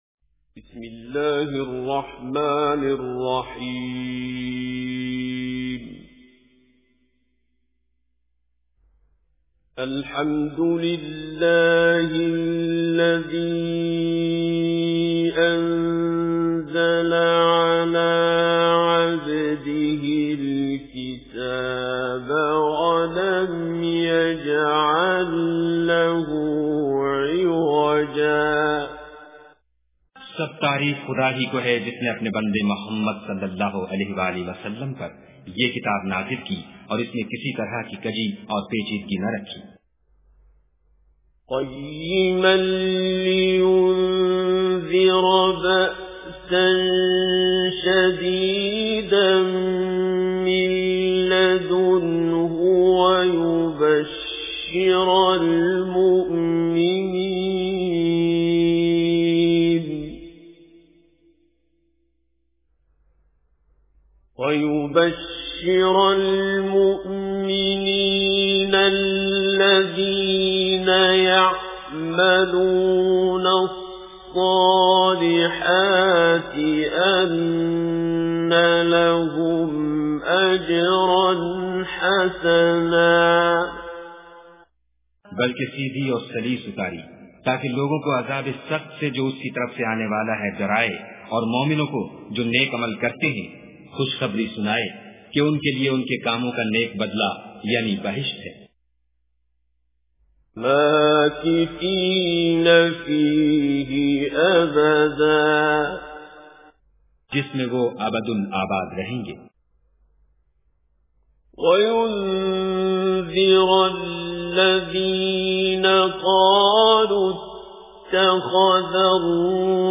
Surah Kahf Tilawat with Urdu Translation